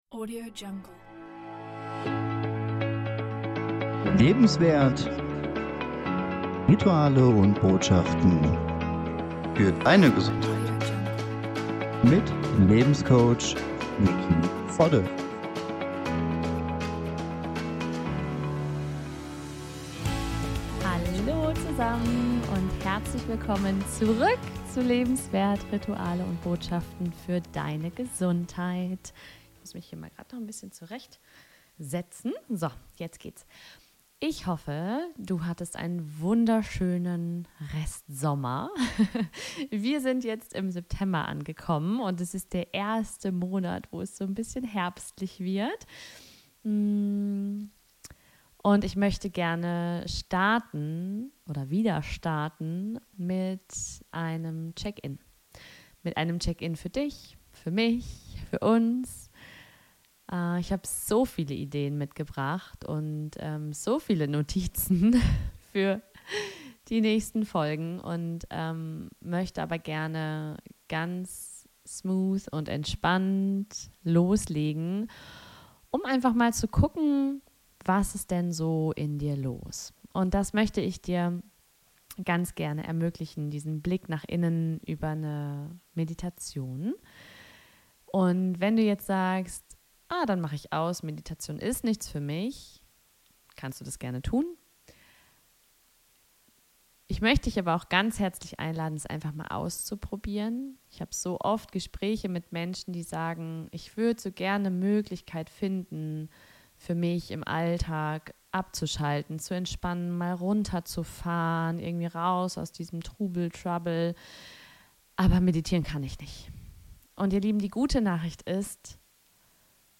Nutze diese angeleitete Meditation um Dich selbst zu beschenken mit dem Gefühl und dem Gedanken oder der Tat, die du gerade wirklich brauchst. Tief in dir finden wir die Antwort gemeinsam.